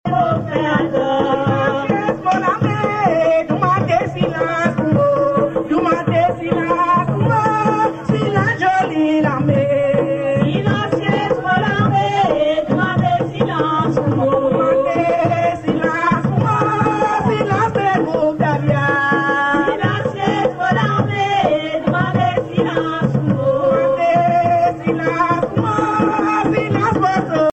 groupe folklorique Dahlia
danse : grajé (créole)
Pièce musicale inédite